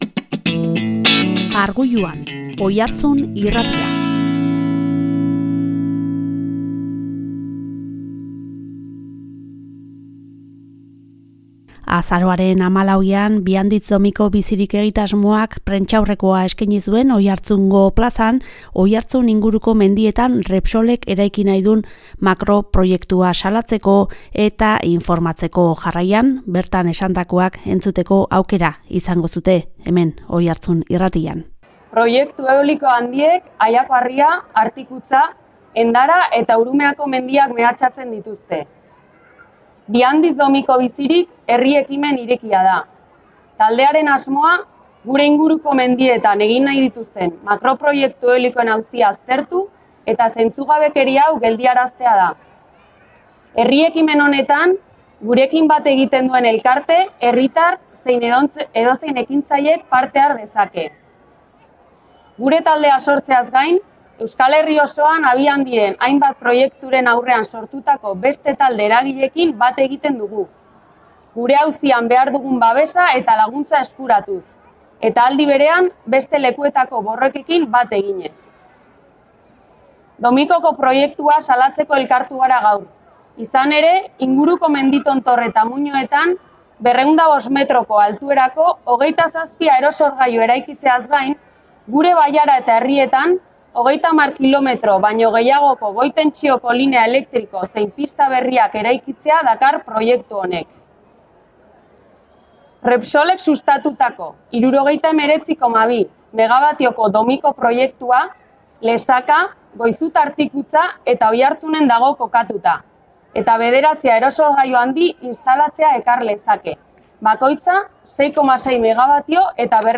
Oiartzun inguruko mendietan Repsolek eraiki nahi duen makro proiektua salatzeko, Bianditz-Domiko Bizirik egitasmoak agerraldia egin zuen azaroaren 14ean Oiartzungo plazan. Bertan esandakoa jaso zuen OIartzun Irratiak.